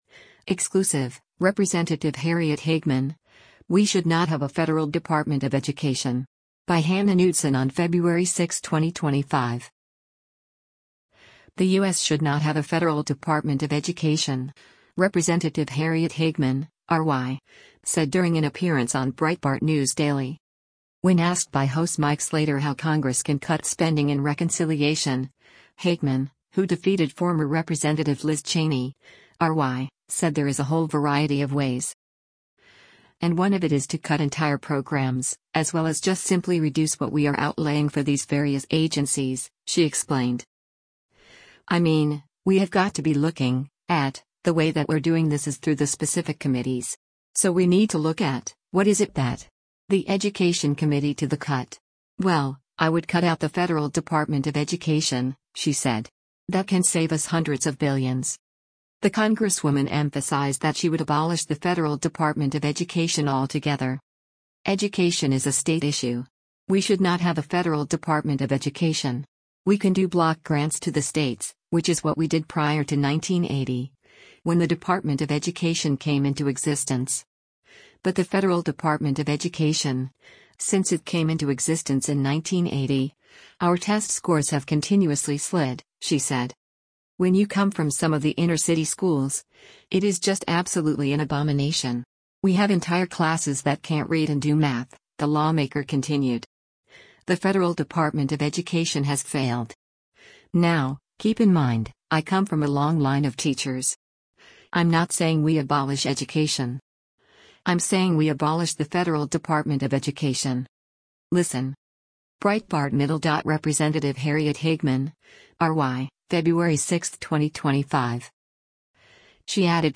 The U.S. should “not have a federal Department of Education,” Rep. Harriet Hageman (R-WY) said during an appearance on Breitbart News Daily.